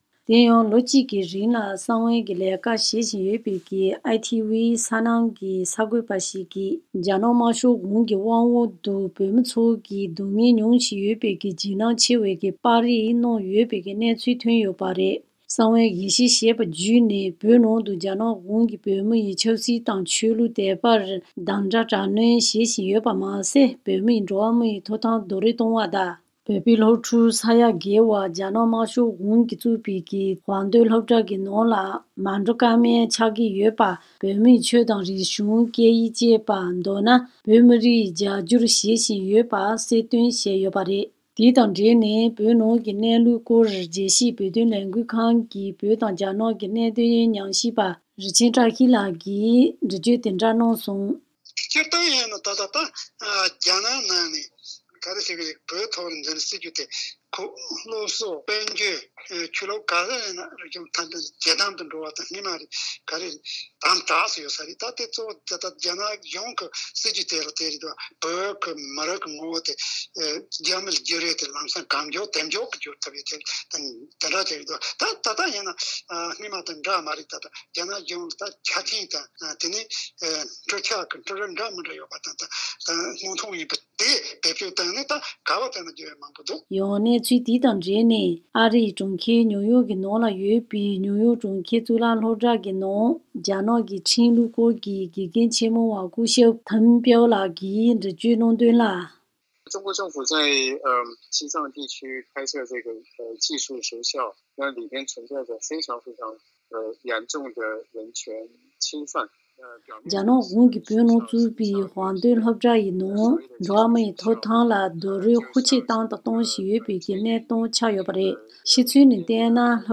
བཅའ་འདྲི་དང་གནས་ཚུལ་ཕྱོགས་བསྡུས་བྱས་པར་གསན་རོགས་ཞུ།